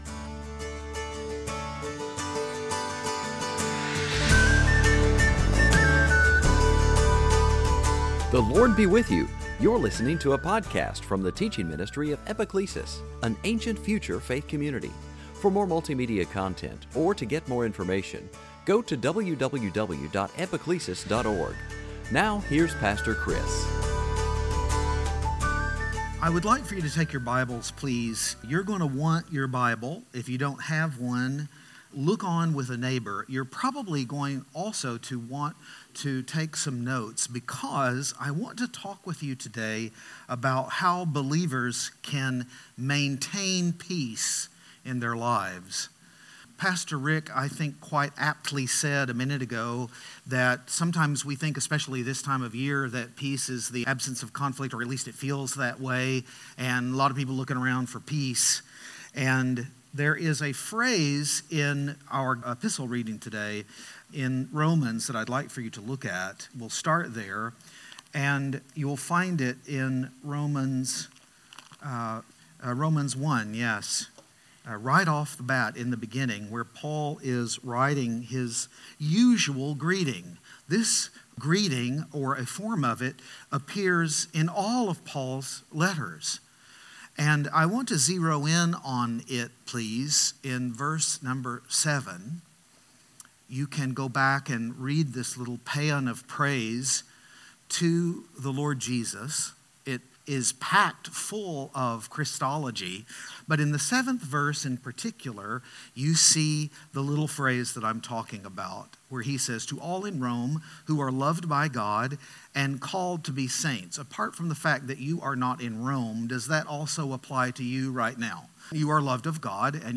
2019 Sunday Teaching Advent death flesh Fruit of the Spirit meditation peace prayer vine Advent